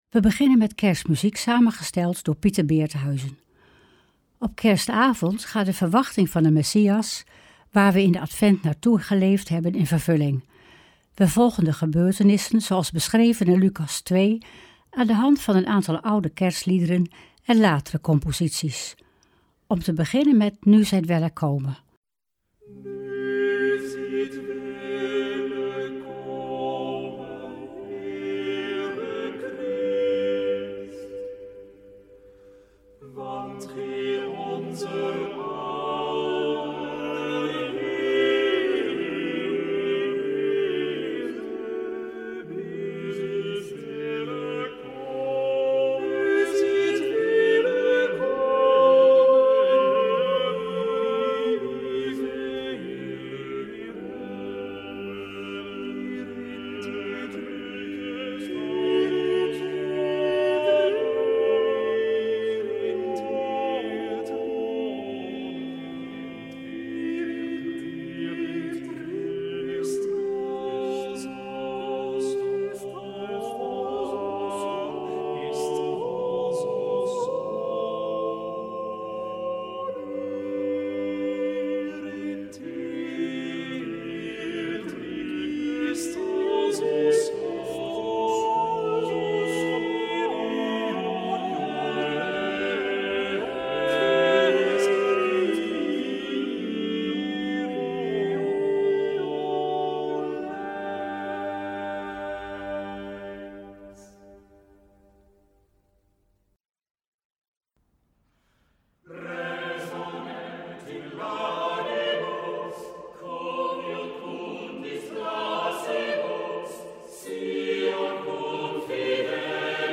Opening van deze Kerstnachtdienst, rechtstreeks vanuit onze studio.